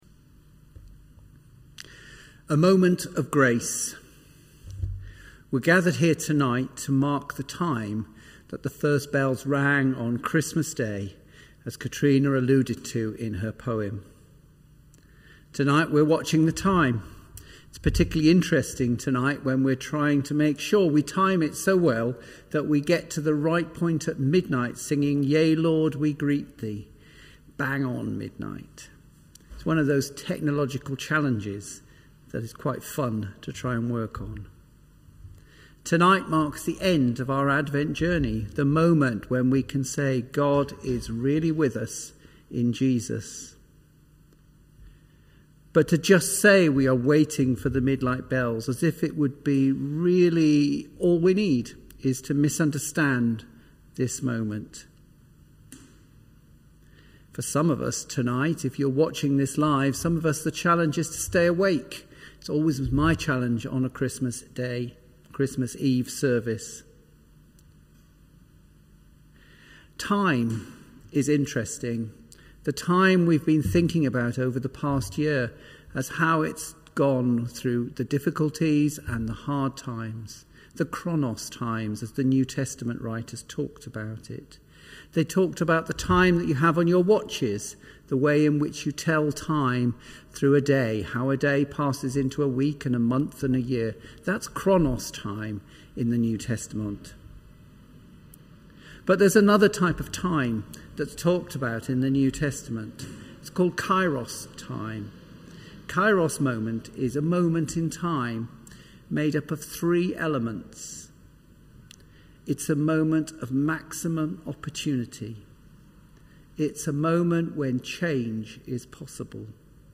ChristmasEveSermon.mp3